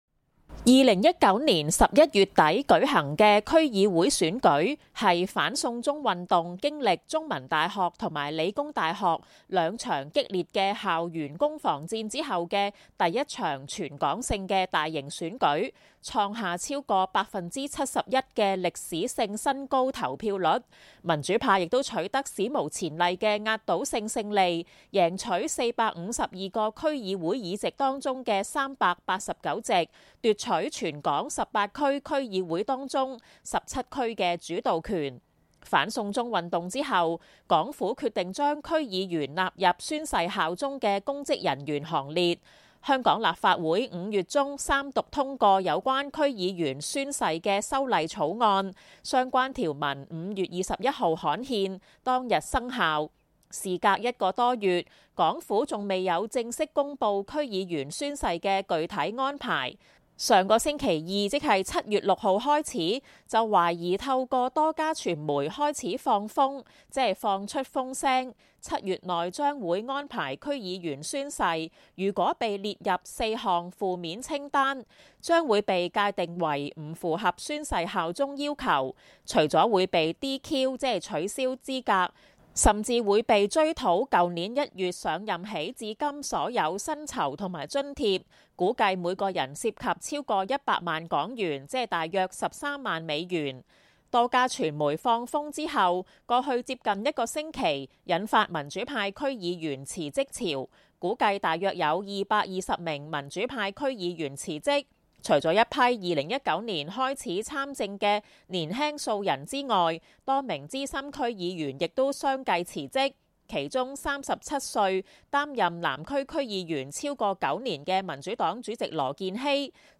民主黨主席羅健熙星期日宣佈辭任南區區議會主席，他星期二接受該黨前主席劉慧卿網台節目訪問表示，辭任區議員不是因為恐懼破產等因素，他又批評港府故意維持模糊狀態更失信於民。羅健熙強調，民主黨會繼續維持政黨的角色，不會因為辭職潮解散。